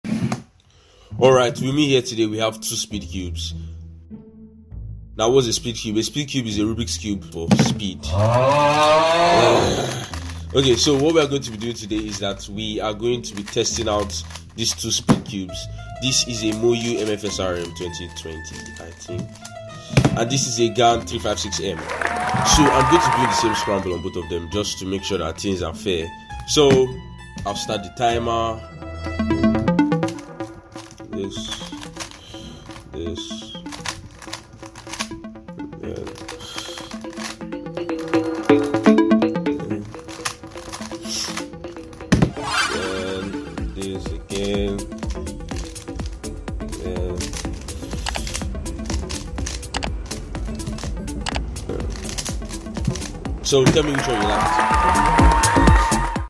WHAT Rubiks Cubes sound like sound effects free download